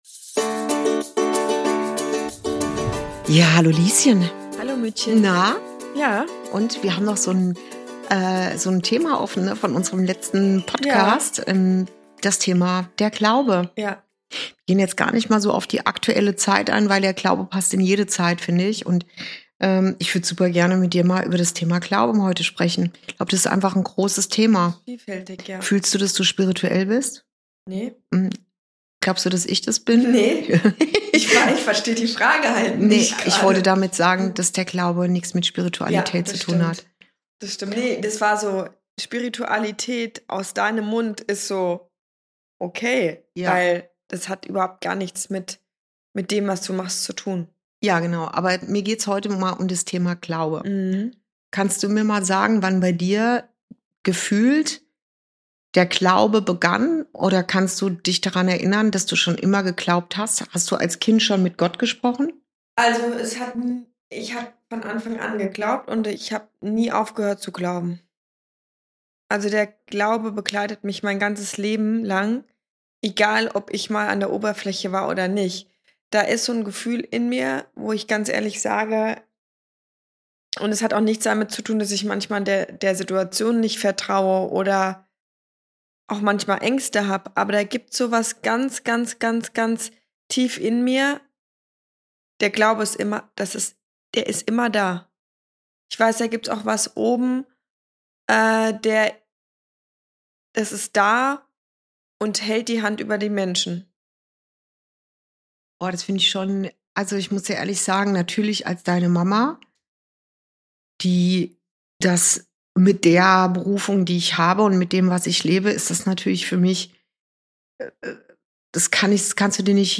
Folge 33: Getragen vom Glauben – Wie Vertrauen dich durch jede Herausforderung trägt ~ Inside Out - Ein Gespräch zwischen Mutter und Tochter Podcast